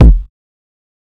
Kick (Special).wav